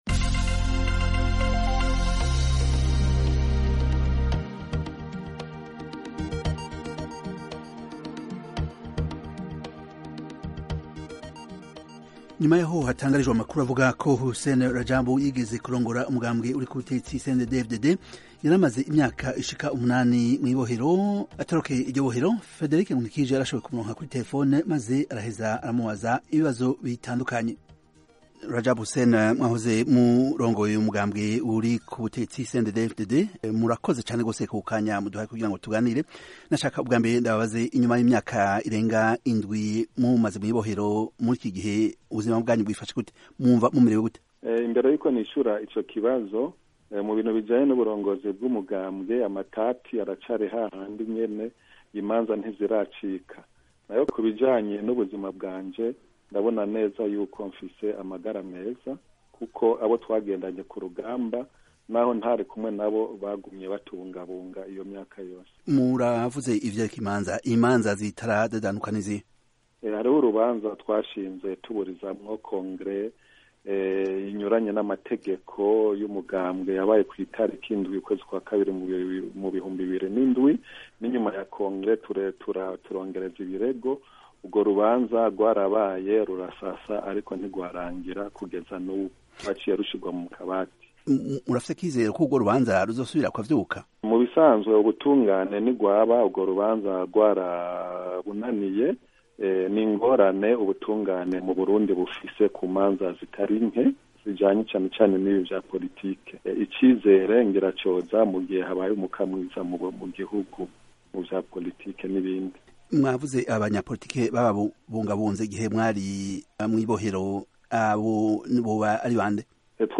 Ikiganiro Hussein Radjabu Yahaye Ijwi Ry'Amerika